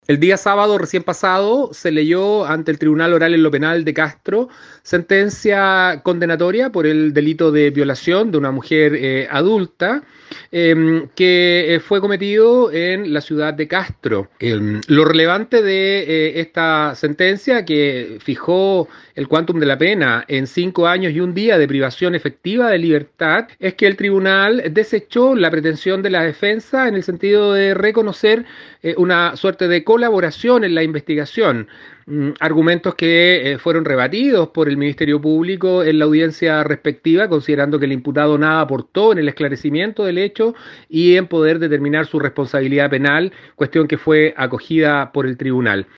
El juicio se desarrolló la semana pasada y acerca de lo determinado por la instancia judicial, entregó declaraciones el fiscal jefe de Castro, Enrique Canales.